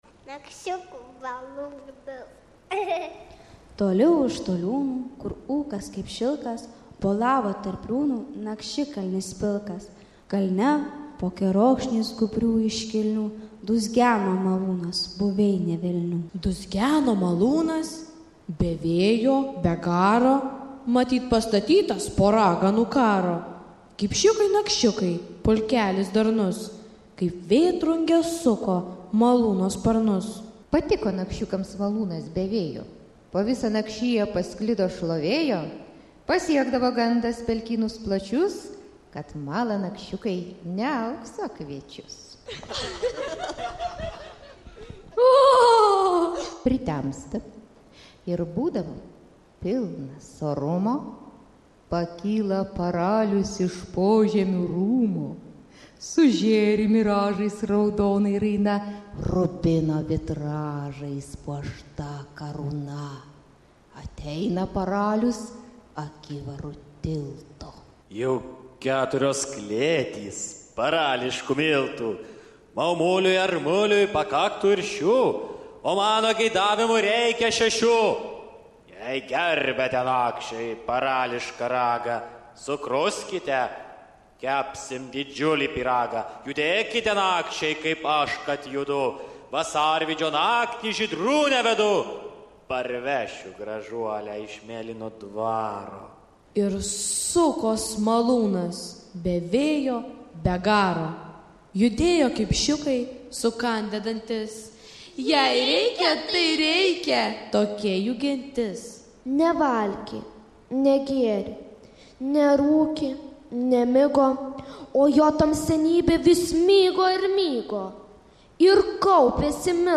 Įrašyta mėgėjiškoje Kukumbalio studijoje, 2008 m.